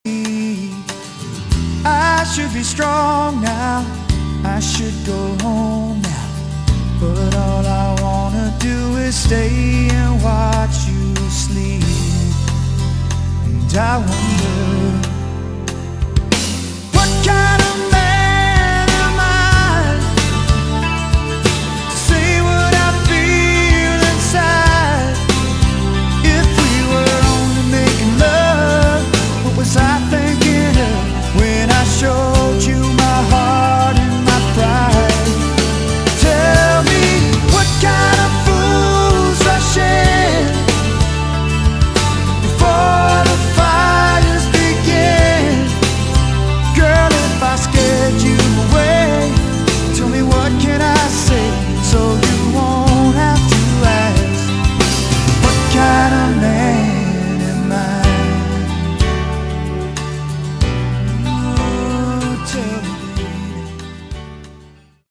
Category: Westcoast AOR